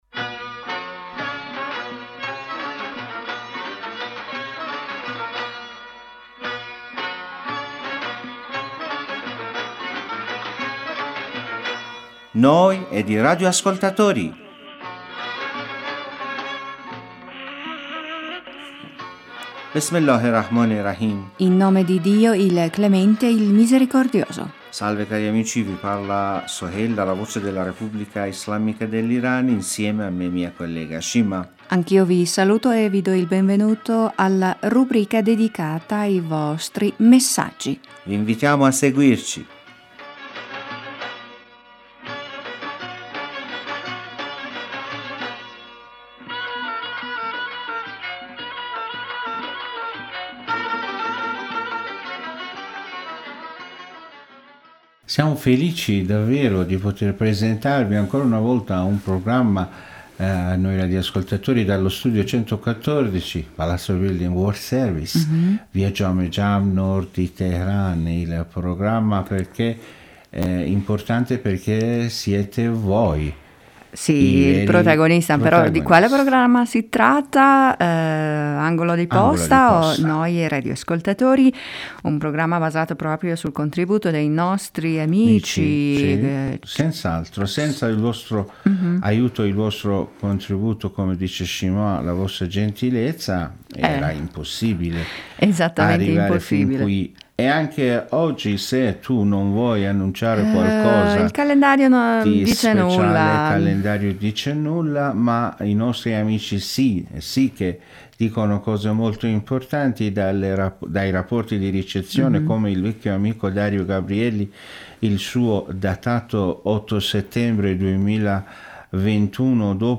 In questa puntata potete ascoltare una bella canzone folcloristica iraniana.